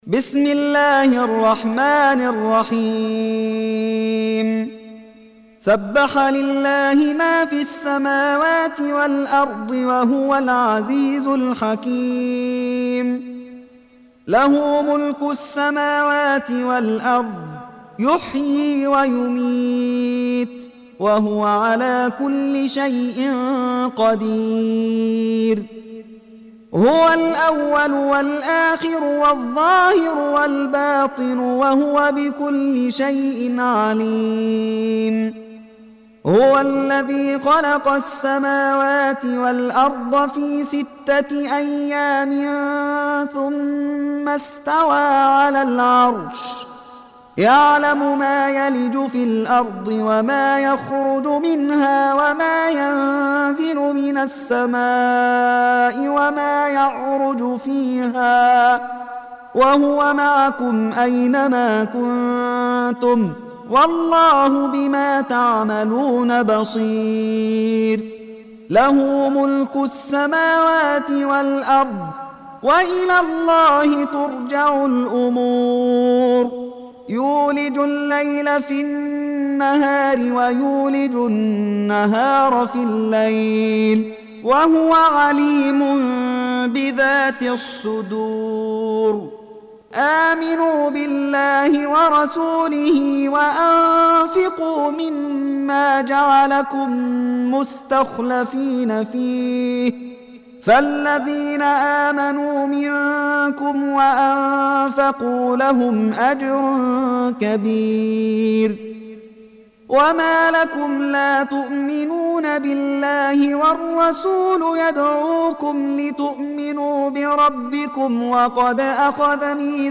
Surah Sequence تتابع السورة Download Surah حمّل السورة Reciting Murattalah Audio for 57. Surah Al-Had�d سورة الحديد N.B *Surah Includes Al-Basmalah Reciters Sequents تتابع التلاوات Reciters Repeats تكرار التلاوات